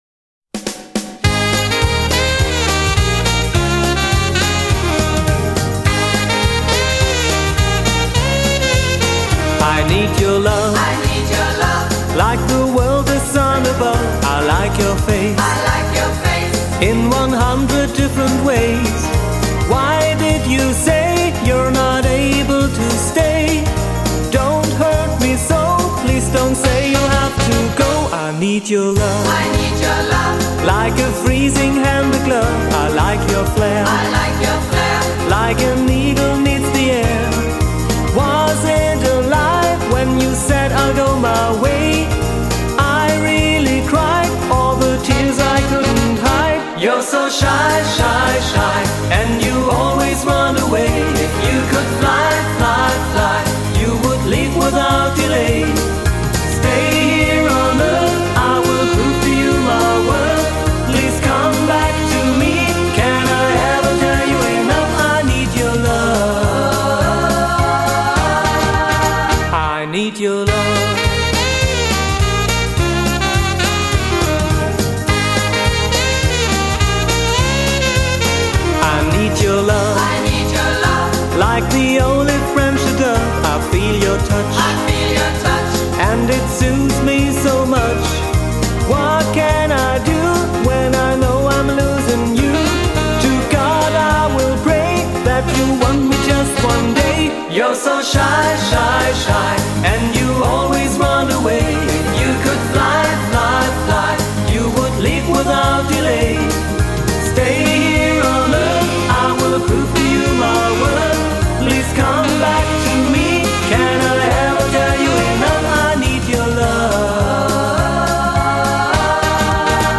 07 Quickstep